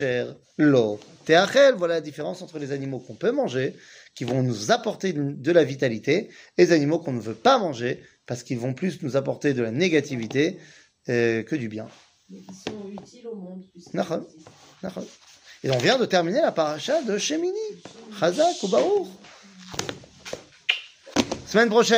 שיעור מ 06 דצמבר 2023